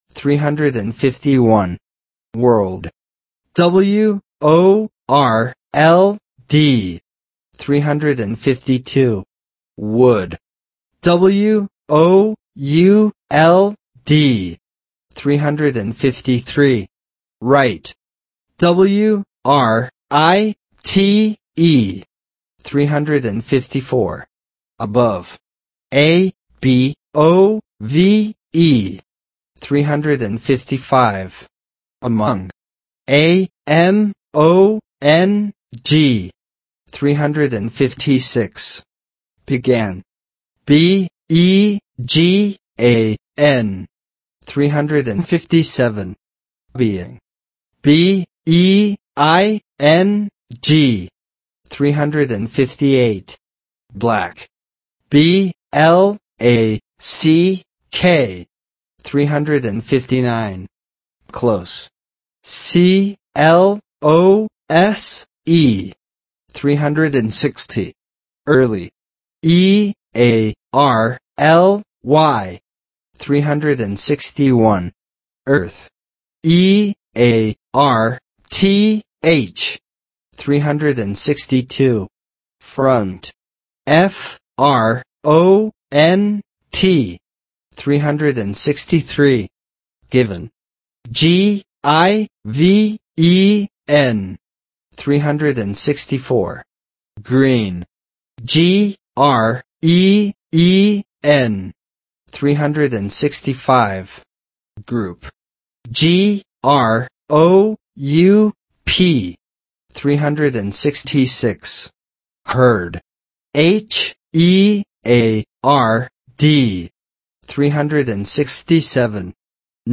Spelling Exercises